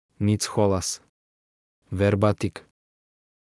Nicholas — Male Serbian AI voice
Nicholas is a male AI voice for Serbian (Latin, Serbia).
Voice sample
Listen to Nicholas's male Serbian voice.
Nicholas delivers clear pronunciation with authentic Latin, Serbia Serbian intonation, making your content sound professionally produced.